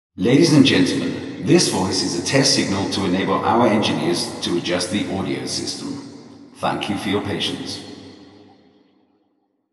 Um die Simulationen im Vorfeld „hörbar“ zu machen, haben wir zusätzlich eine Auralisation erstellt, die den Entscheidern einen realistischen Höreindruck von der Beschallungsanlage vermittelte.